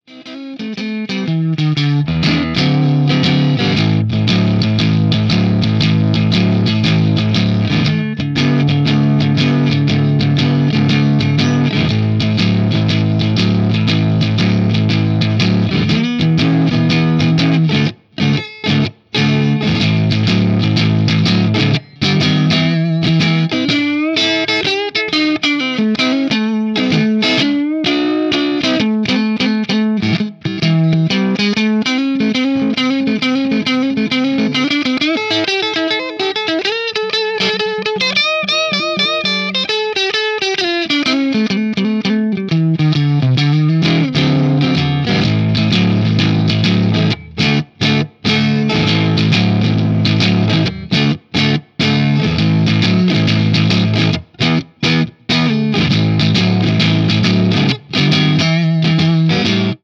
Totally unstructured, free form clips!
With the JTM I used my TTA Trinity cab, the Marshall with Greenbacks, and the Mesa Recto with V-30s.
I set up 2 mics this time – a Shure SM57 and an Audix i5. The i5 has a more pronounced top end and bigger bottom and compliments the 57’s midrange thing really well.
E_JTM4_Input1Hi_PRSMira_SingleCoilNeck_Greens.mp3